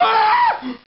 PERSON-Scream+3
Tags: combat